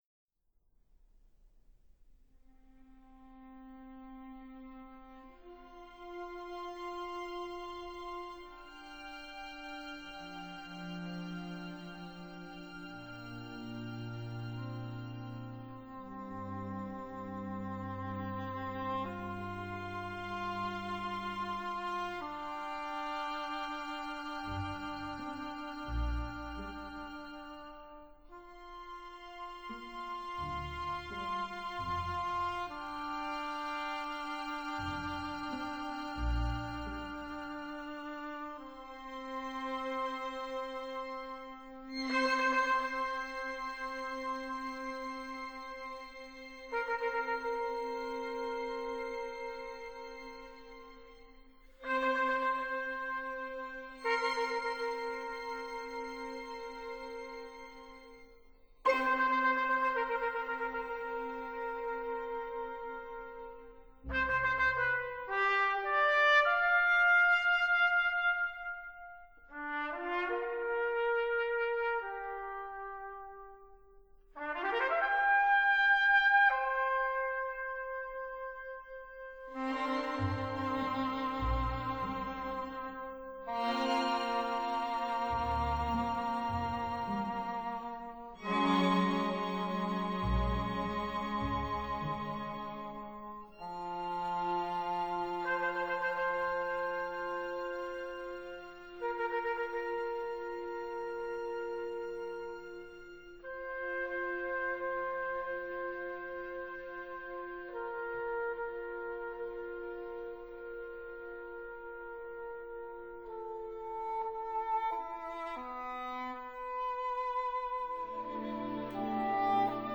for cor anglais, trumpet and strings
cor anglais
trumpet